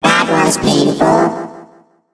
evil_rick_hurt_vo_02.ogg